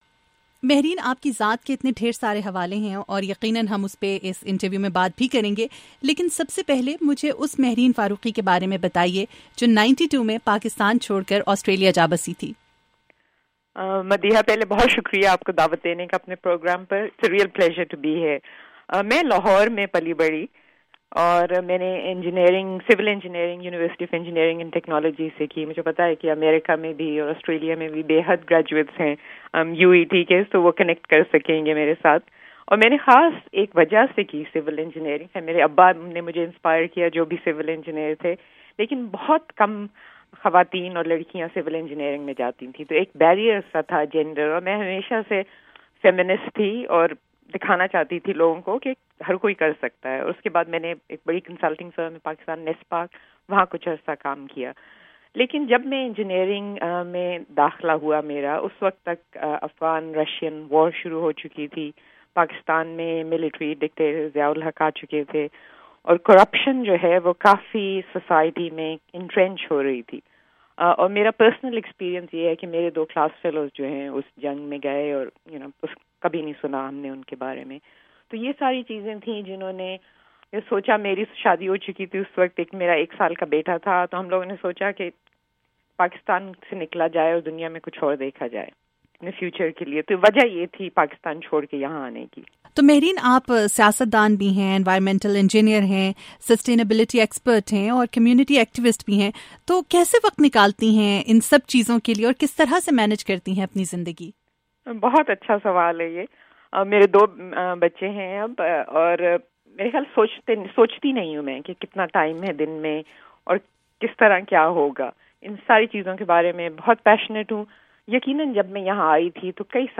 آسٹریلین پارلیمنٹ کی تاریخ میں پہلی مسلمان اور پاکستانی آسٹریلین خاتون رکن منتخب ہونے والی مہرین فاروقی کی وائس آف امریکہ سے خصوصی گفتگو۔